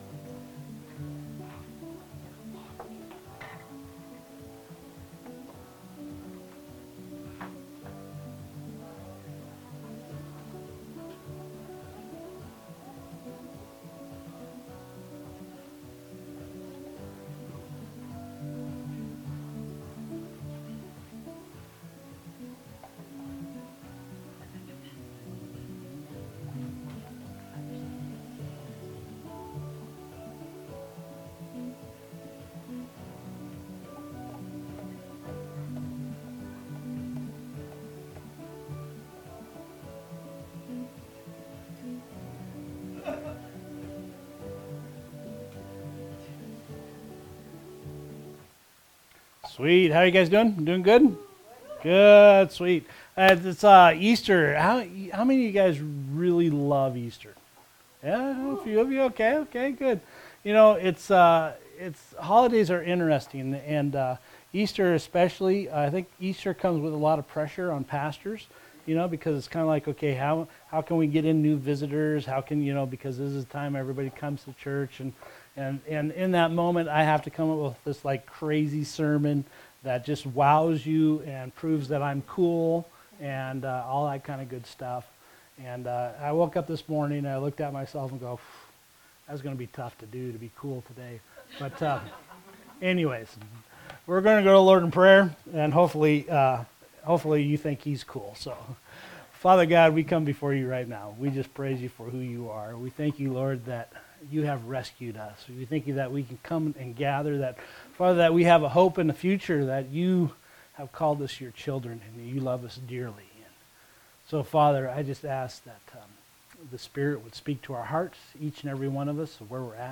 Sermons | Explore Church